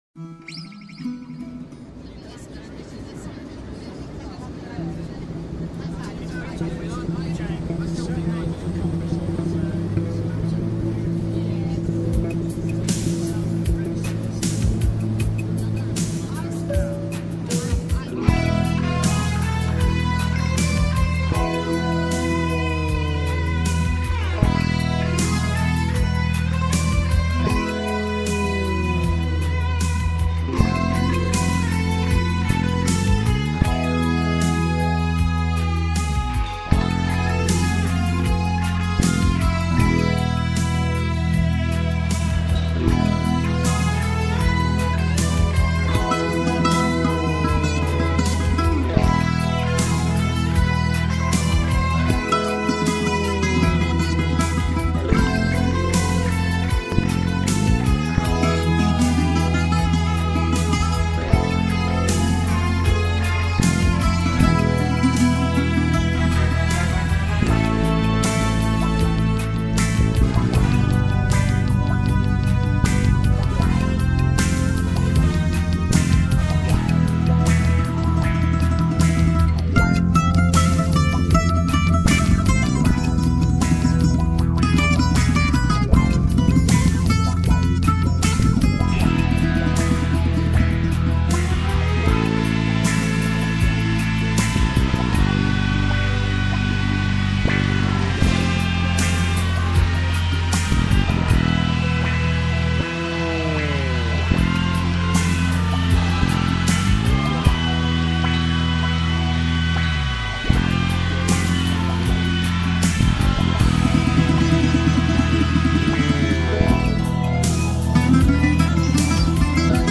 Σε μια μεγάλη συνέντευξη μας διηγείται το ξεκίνημα του από το 1970 ακόμα, την μετοίκηση του στην Νορβηγία λίγο αργότερα και την απαρχή της προσωπικής του δουλειάς μετά τα συγκροτήματα.